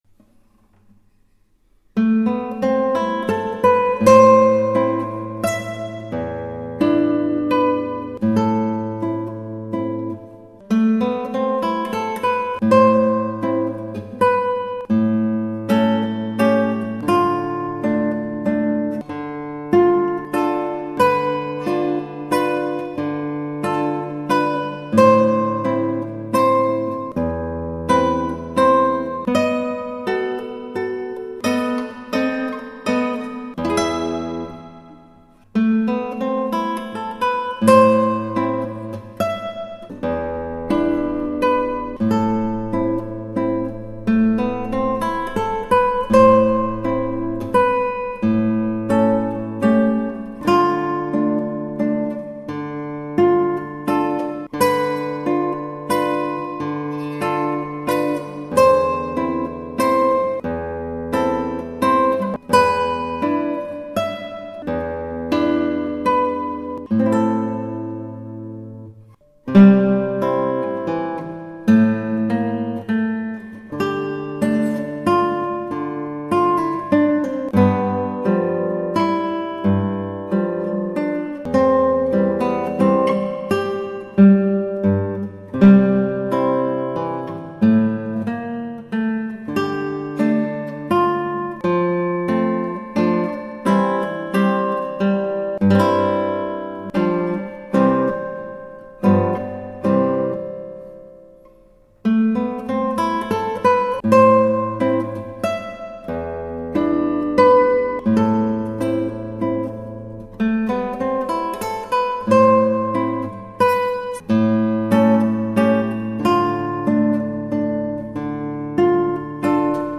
マリアルイサ、ヤイリギターで